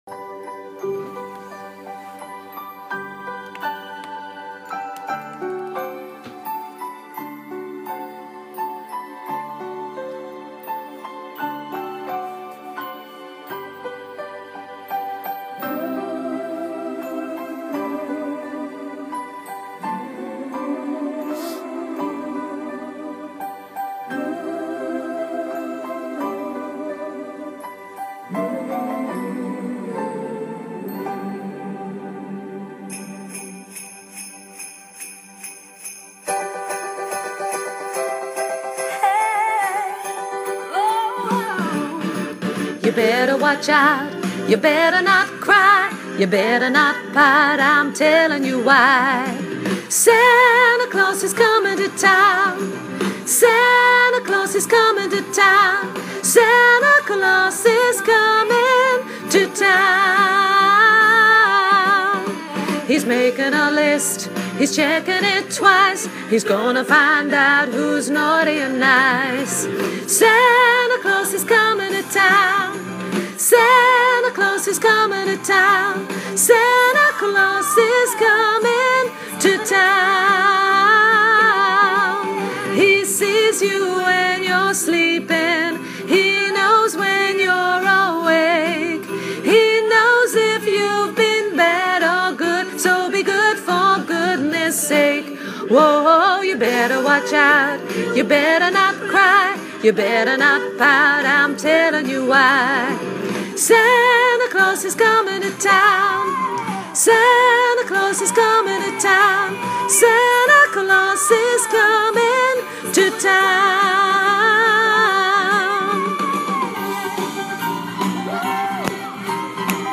lead